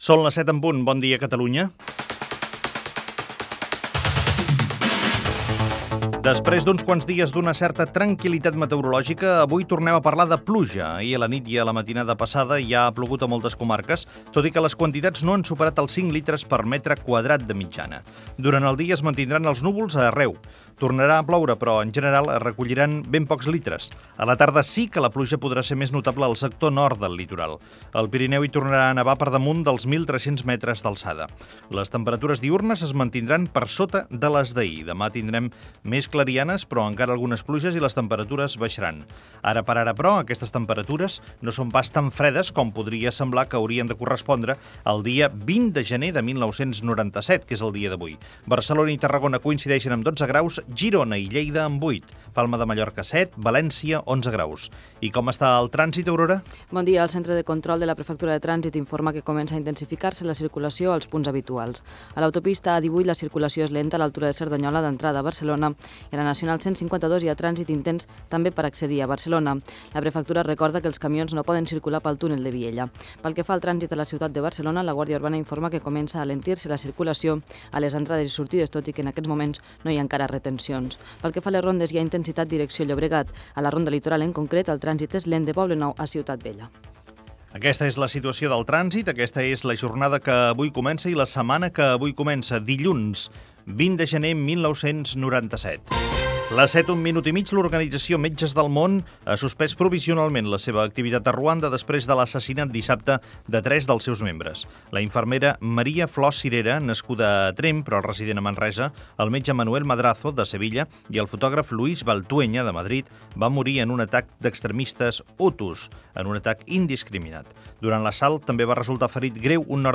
Info-entreteniment
Fragment extret de l'arxiu sonor de COM Ràdio.